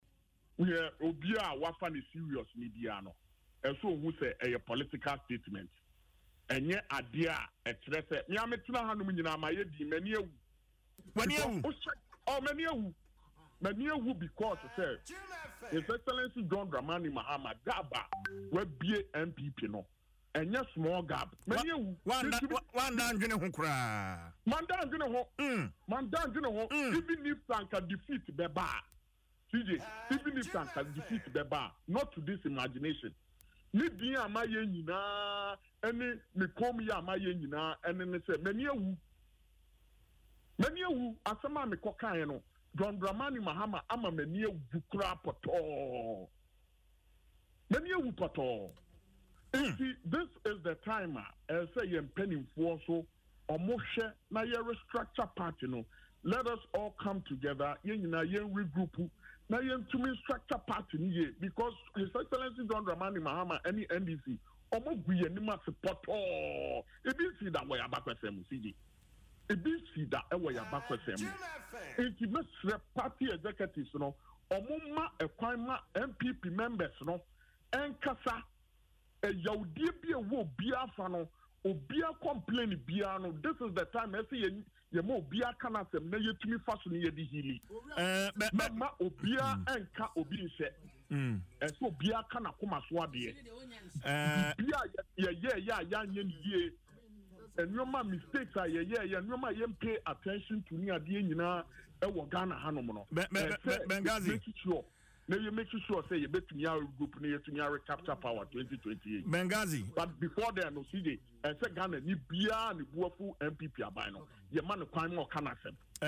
Speaking on Adom FM’s morning show